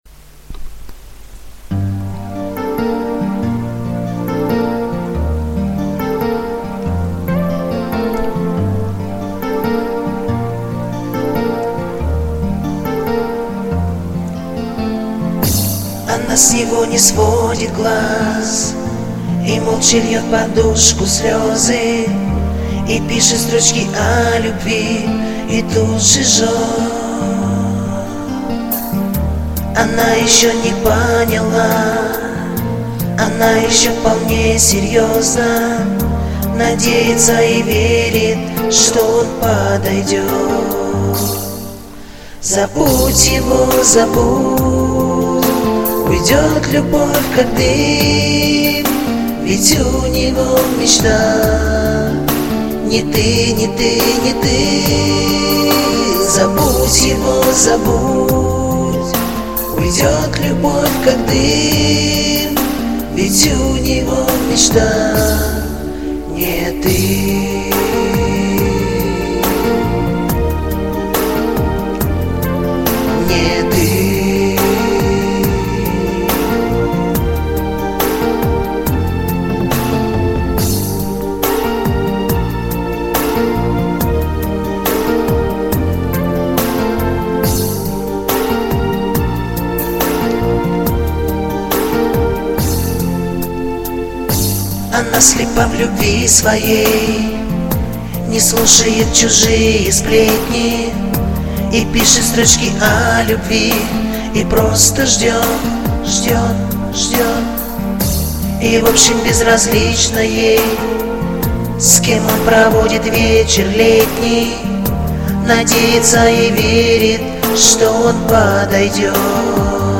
И бэки все пропел!
Качество записи канеш страдает!...